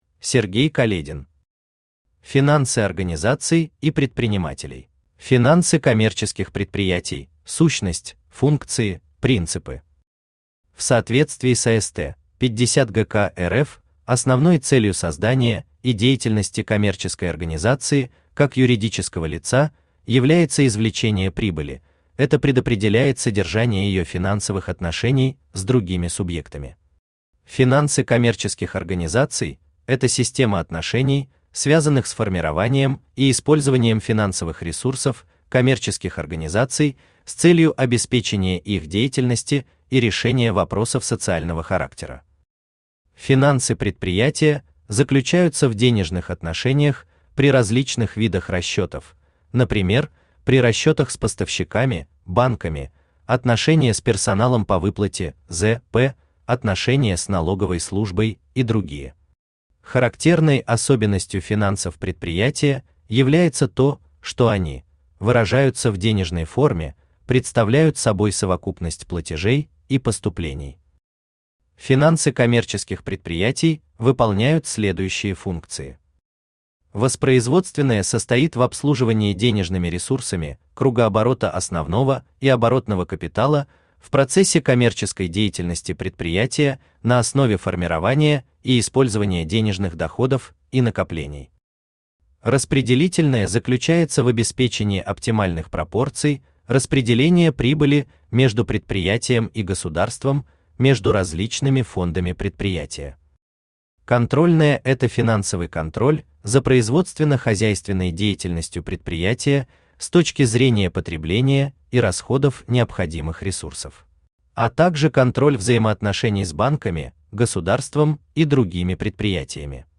Aудиокнига Финансы организаций и предпринимателей Автор Сергей Каледин Читает аудиокнигу Авточтец ЛитРес.